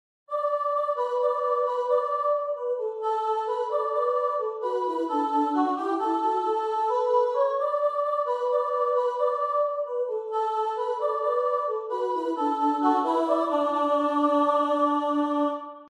U/2-part Choral Unison and/or 2-part on bonus page.
Descant Descant for last refrain.